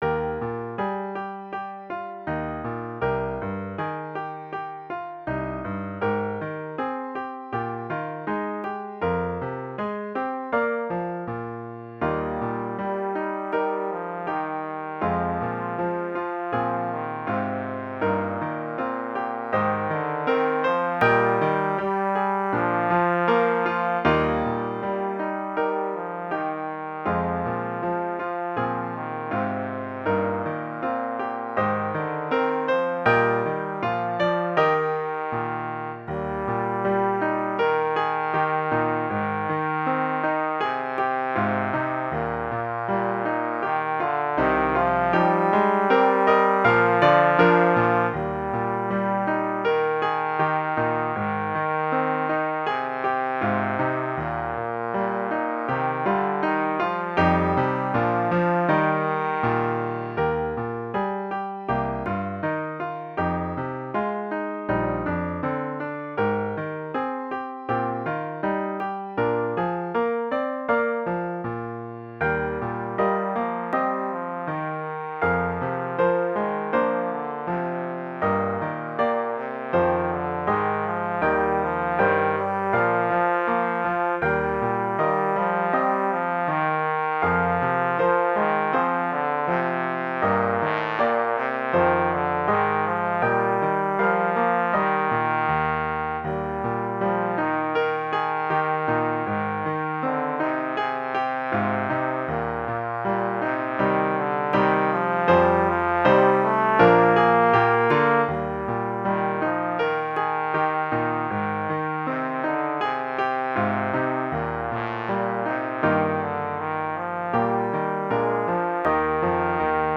Intermediate Instrumental Solo with Piano Accompaniment.
Christian, Gospel, Sacred.
puts the sacred theme to a gentle, meditative mood.